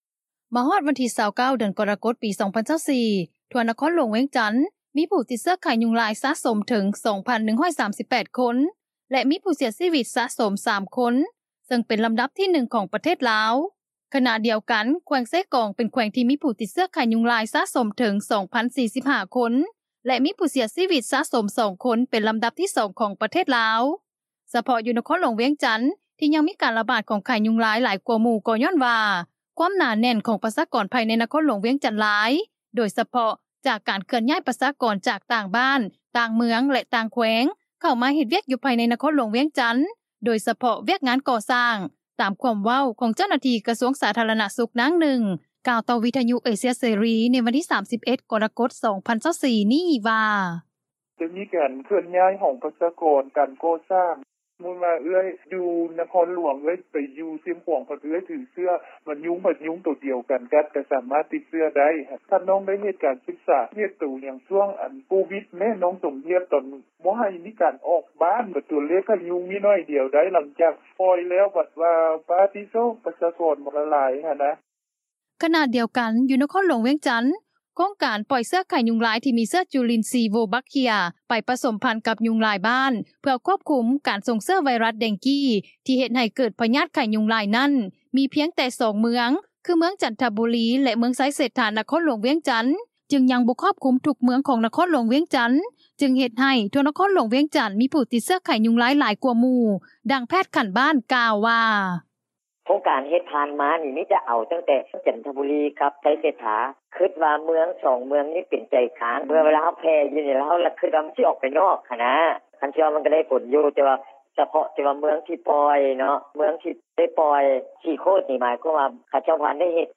ນະຄອນຫຼວງວຽງຈັນ, ເຊກອງ ມີຜູ້ຕິດເຊື້ອໄຂ້ຍຸງລາຍ ເພີ່ມສູງຂຶ້ນ — ຂ່າວລາວ ວິທຍຸເອເຊັຽເສຣີ ພາສາລາວ